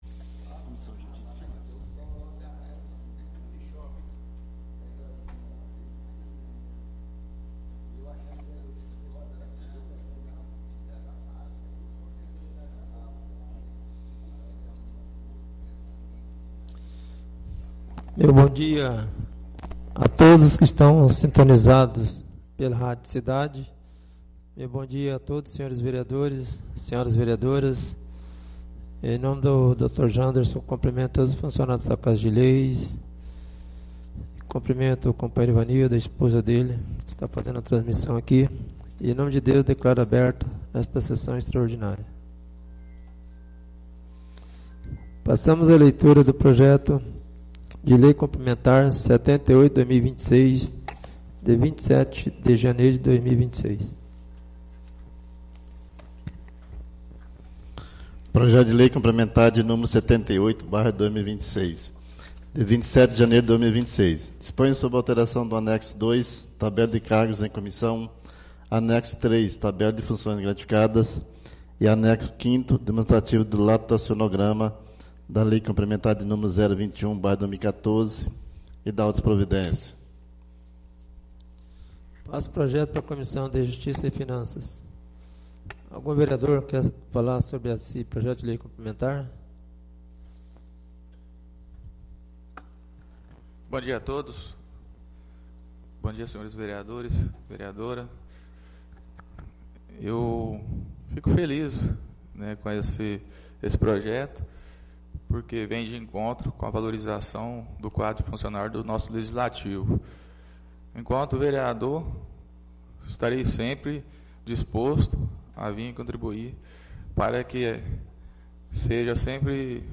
3° SESSÃO EXTRAORDINÁRIA DE 10 DE FEVEREIRO DE 2026 - Câmara Municipal de Figueirópolis d´Oeste